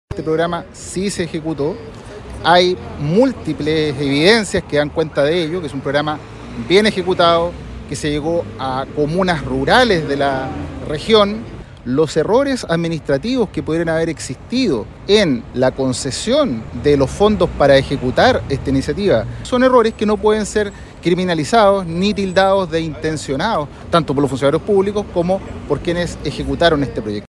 abogado-defensor.mp3